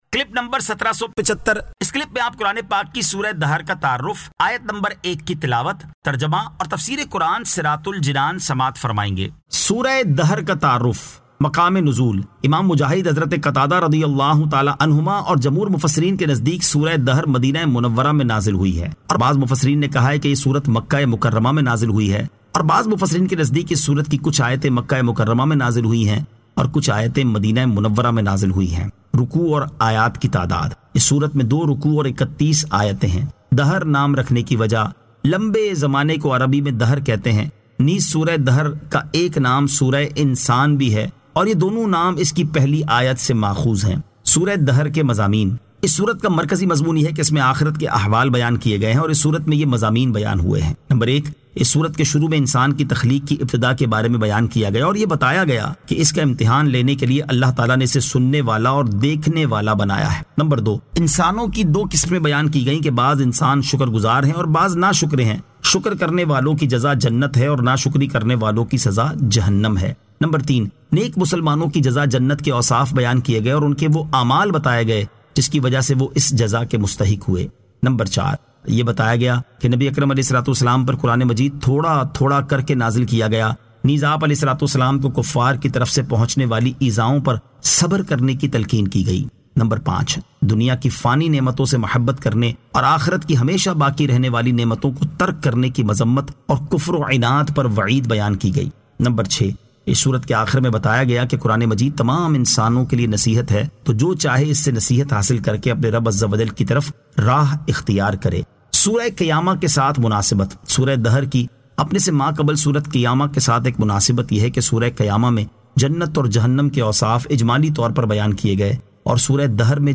Surah Ad-Dahr 01 To 01 Tilawat , Tarjama , Tafseer